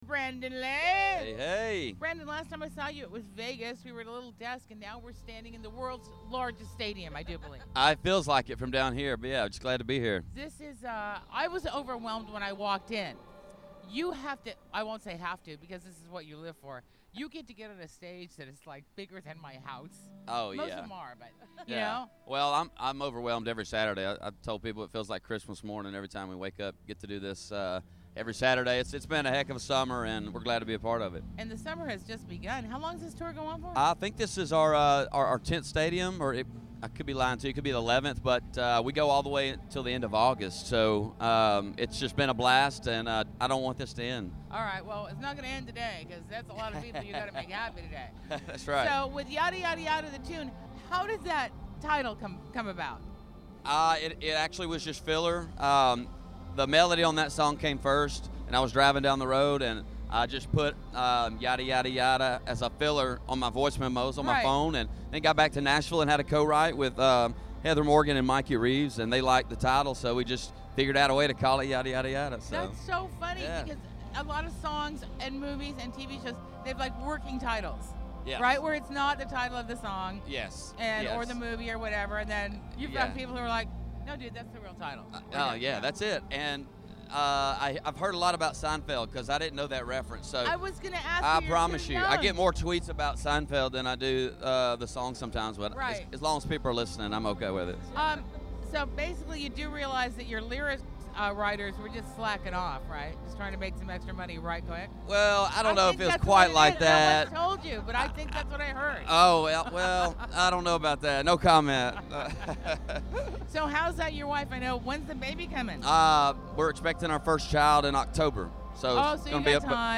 Backstage Broadcast At Trip Around The Sun Tour